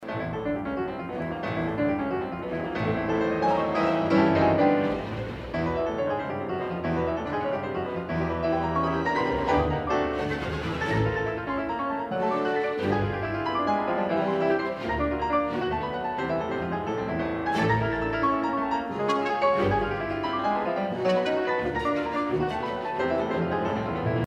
钢琴以断奏奏三连音符